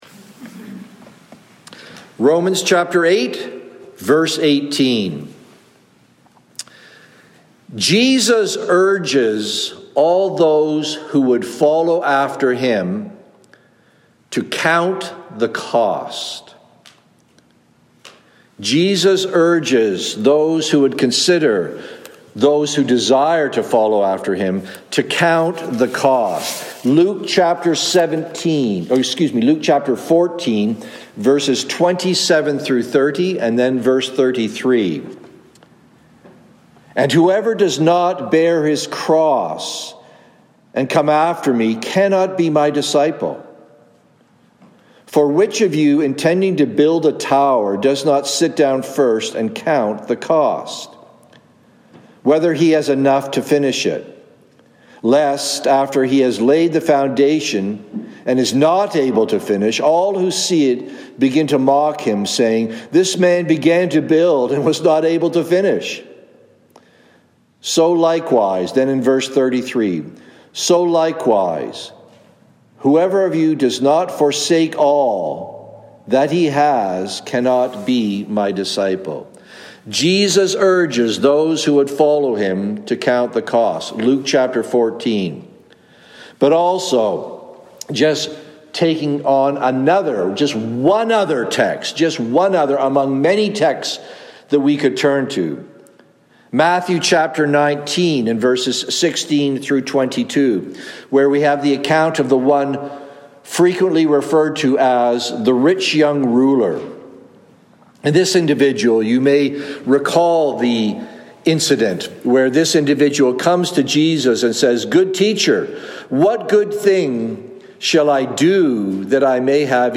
Sermons | Cranbrook Fellowship Baptist